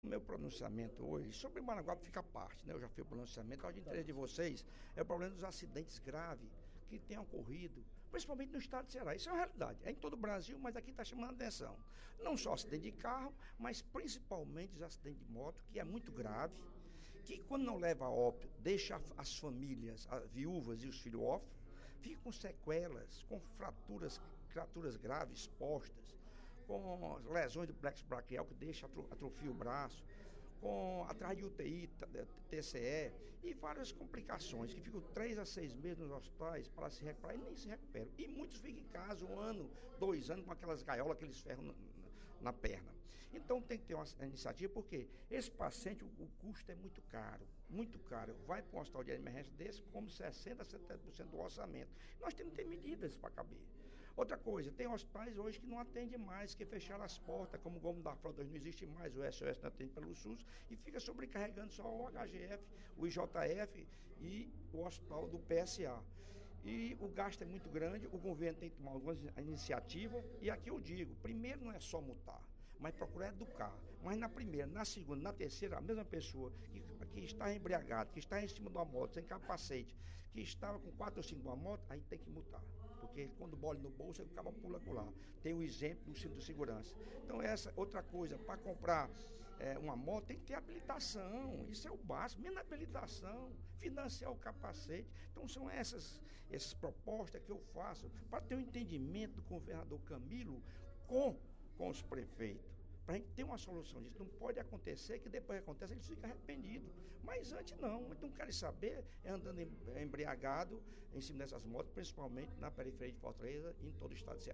O deputado Lucílvio Girão (SD) defendeu, durante o primeiro expediente da sessão plenária desta quinta-feira (28/05), apreensão das motos de condutores que não respeitam a legislação de trânsito.